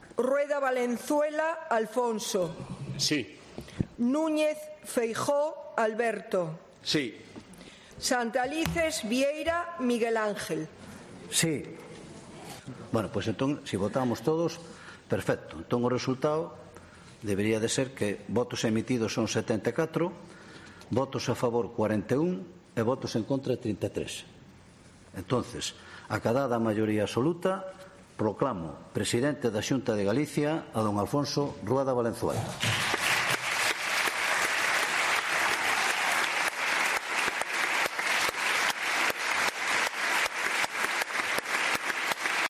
Votaciones de Rueda, Feijóo y el presidente del Parlamento, Miguel Santalices, al nuevo presidente de la Xunta